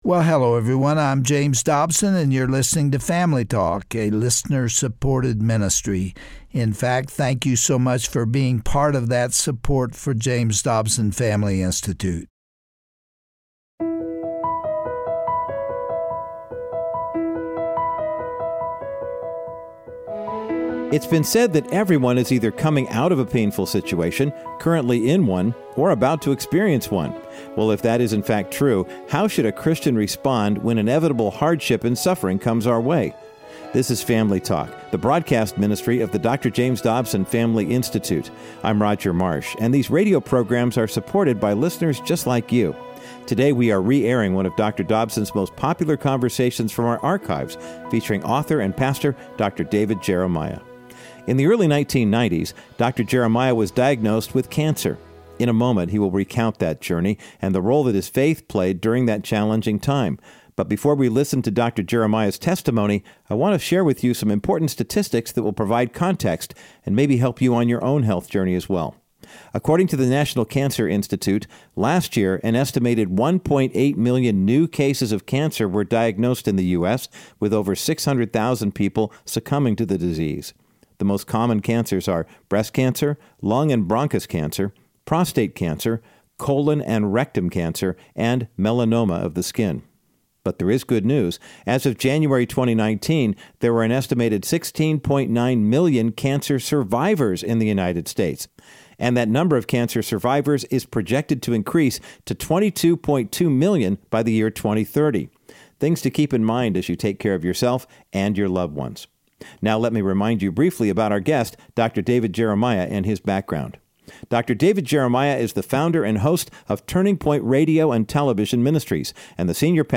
Host Dr. James Dobson
Guest(s):Dr. David Jeremiah